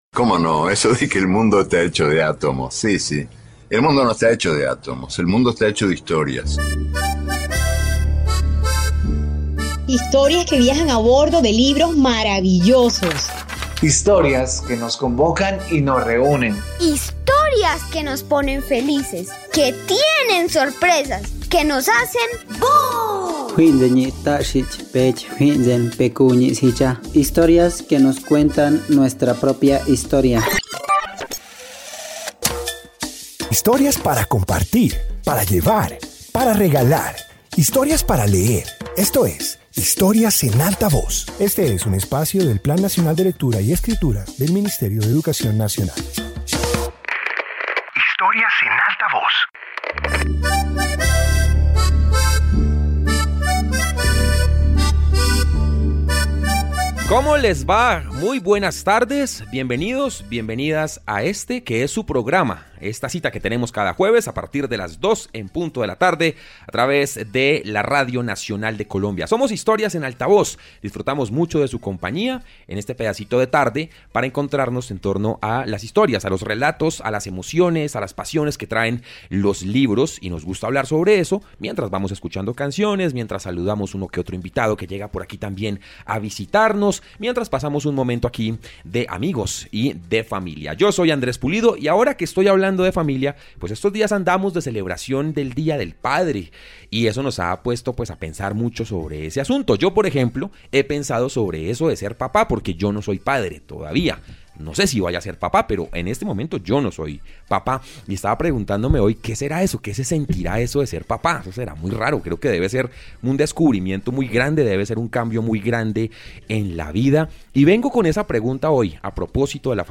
Introducción Este episodio de radio reúne narraciones que giran alrededor de los padres. Presenta lecturas y recuerdos que evocan afectos, aprendizajes y experiencias familiares.